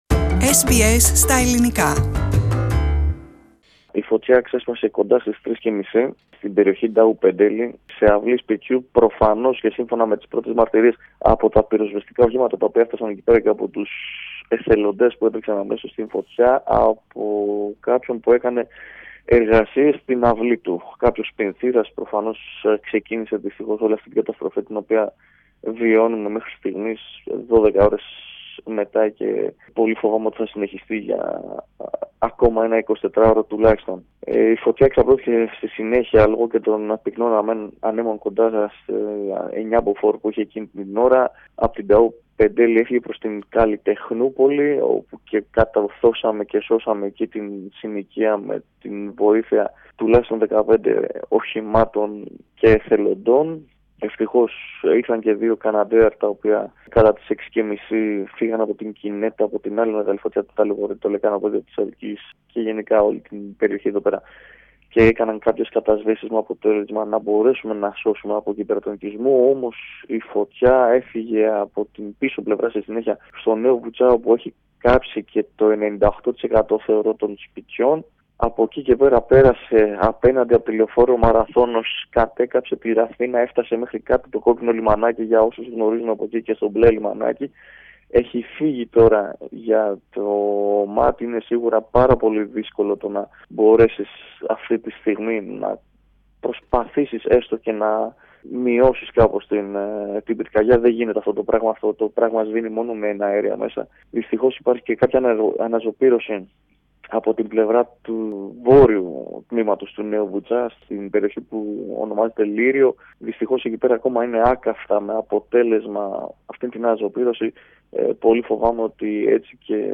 Πατήστε Play στο Podcast που συνοδεύει την κεντρική φωτογραφία για να ακούσετε τη συνέντευξη.